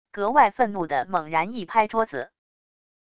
格外愤怒的猛然一拍桌子wav音频